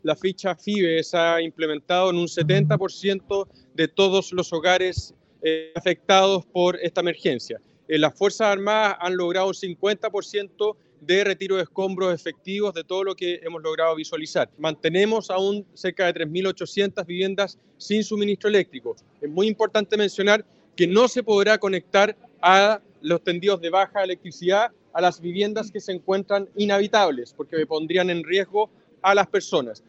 Al respecto, el alcalde de Puerto Varas, Tomás Gárate, informó que se ha aplicado en un 70% la FIBE de todos los hogares siniestrados.
alcalde-puerto-varas.mp3